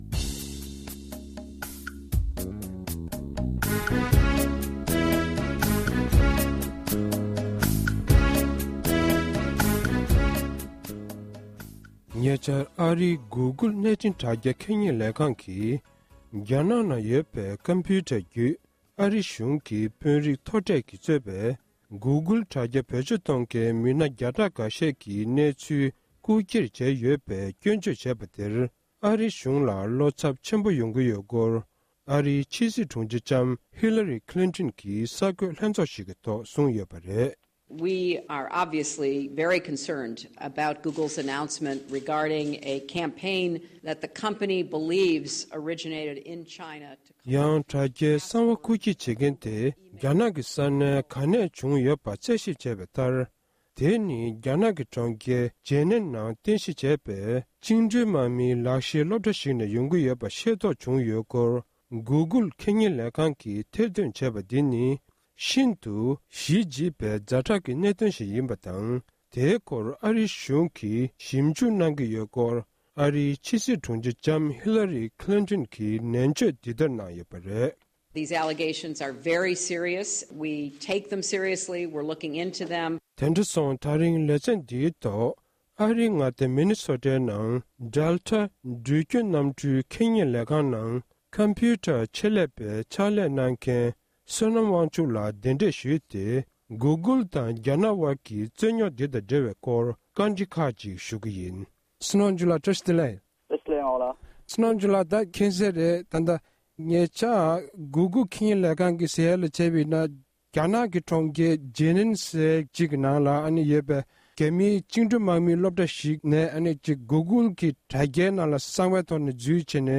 གནས་འདྲི་ཞུས་ཡོད།།